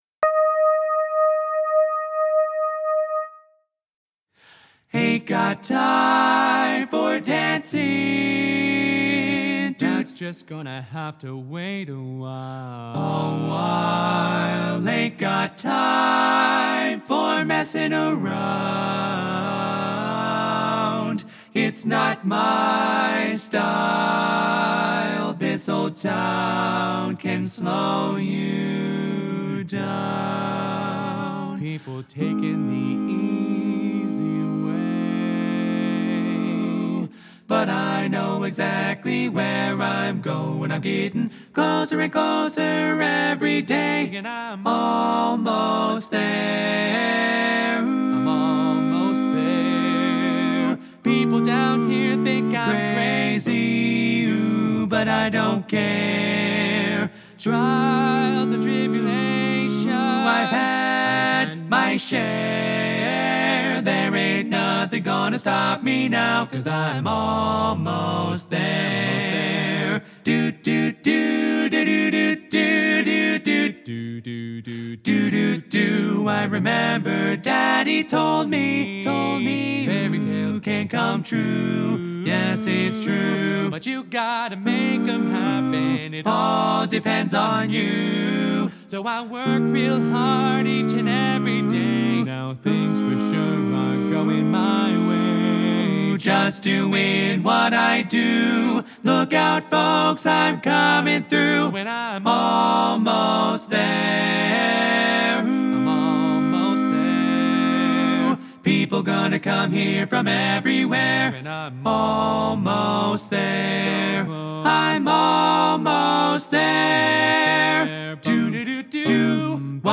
Harmony ReChoired (chorus)
Up-tempo
Barbershop